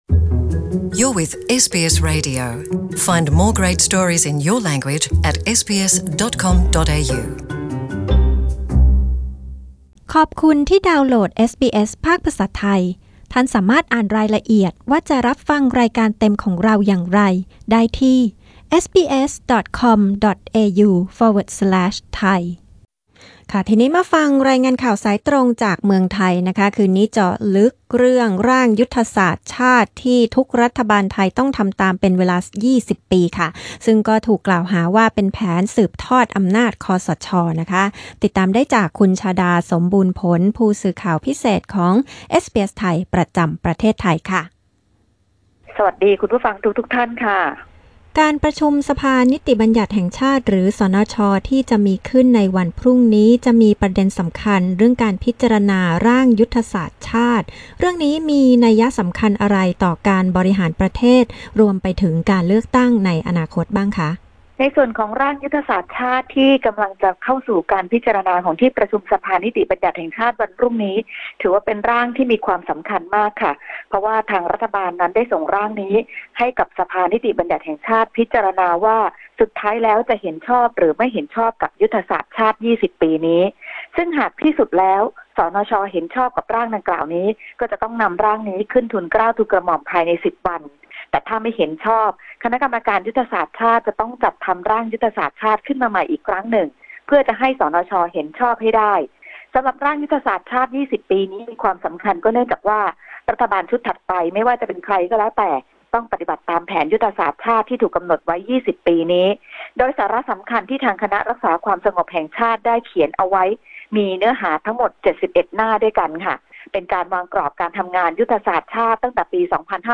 Thai phone-in news 14 JUN 2018